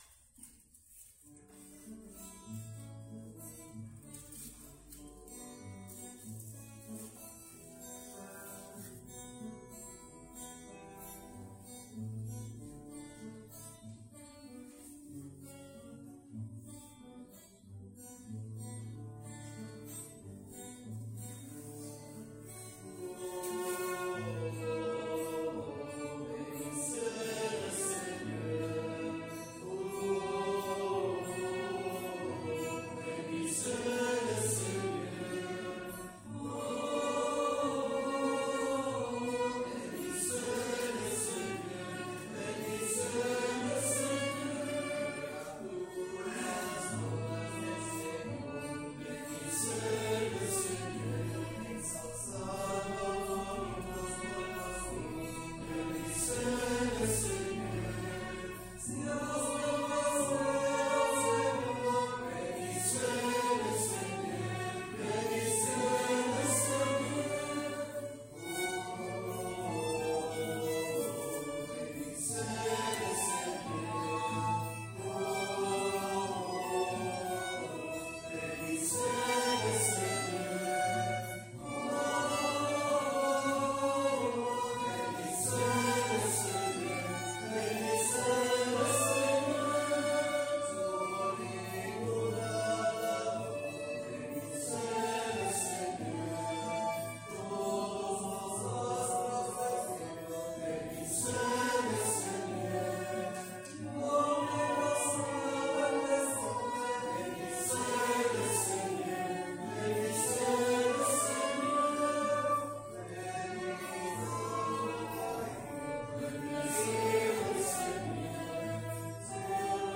Pregària de Taizé a Mataró... des de febrer de 2001
Ermita de Sant Simó - Diumenge 29 de gener de 2023